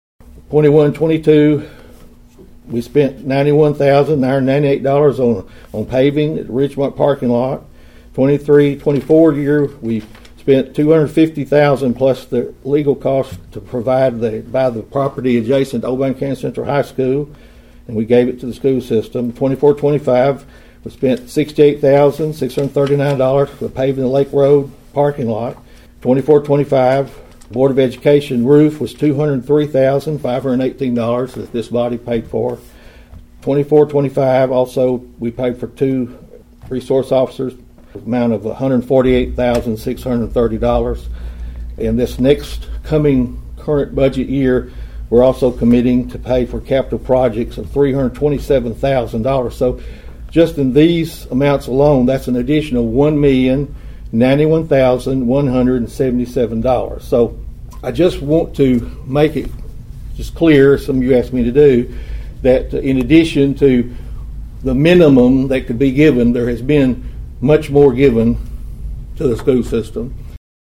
During his nearly 17-minute address to the Budget Committee last week, Mayor Carr said County Commissioners have exceeded their maintenance of effort for the schools.(AUDIO)